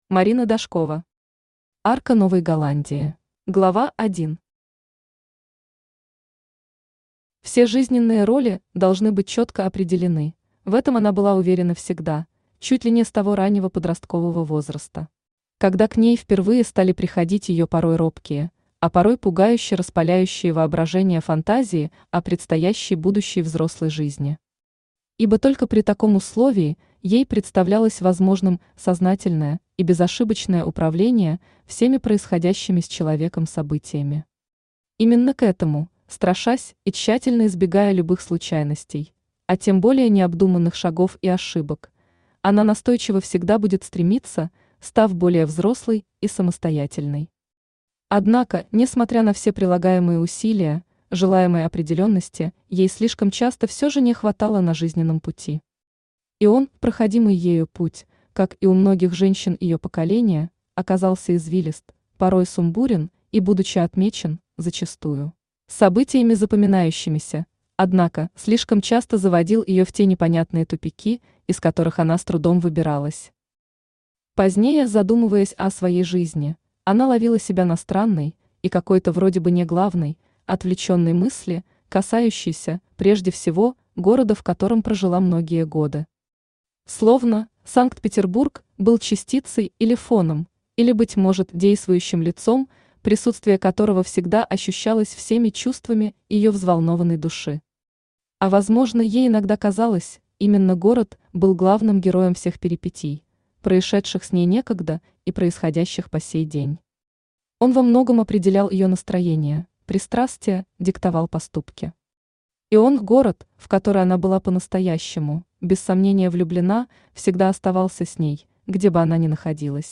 Aудиокнига Арка Новой Голландии Автор Марина Дашкова Читает аудиокнигу Авточтец ЛитРес.